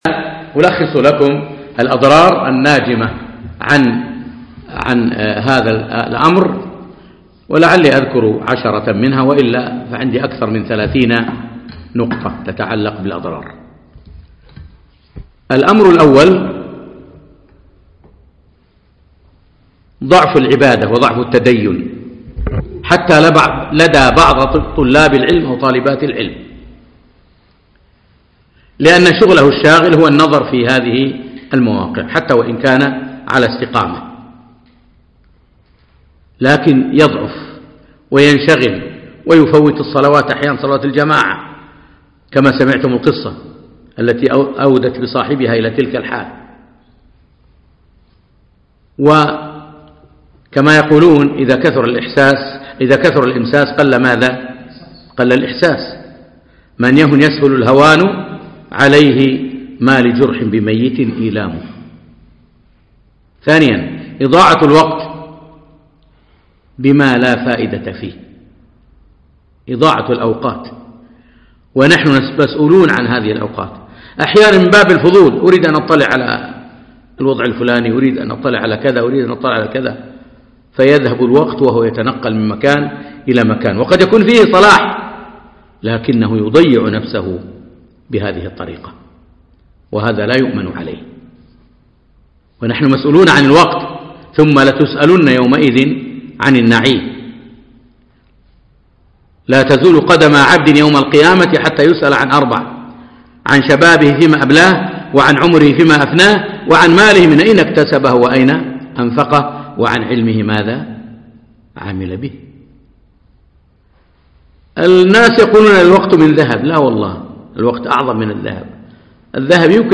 مقتطف من محاضرة ضوابط التعامل مع وسائل التواصل